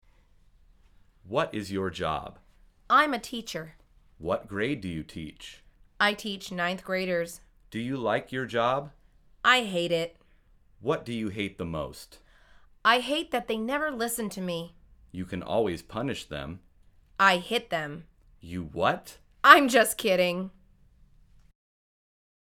مجموعه مکالمات ساده و آسان انگلیسی – درس شماره هشتم از فصل مشاغل: معلم بودن